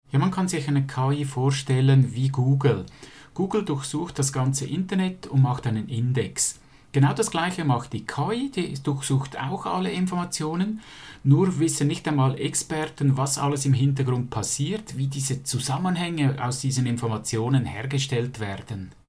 Dieses Interview gibt es auch auf Schwitzerdütsch!